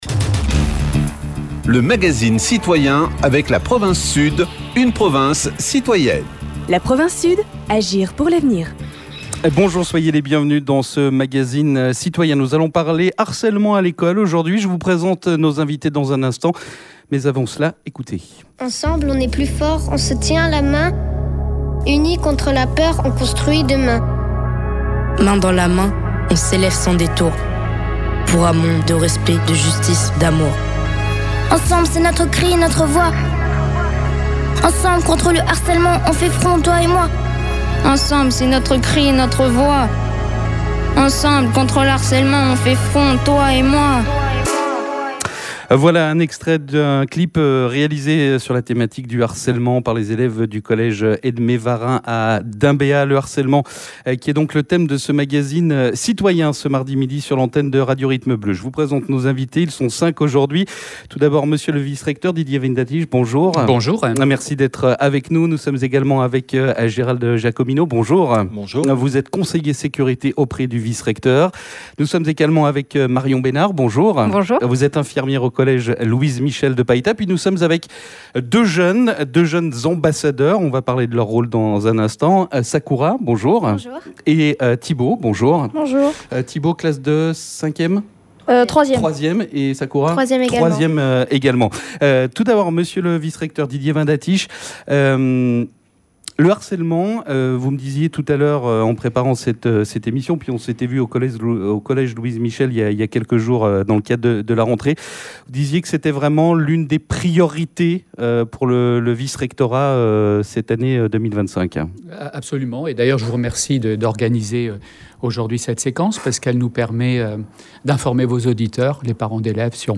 Autant de questions que nous posons à nos invités.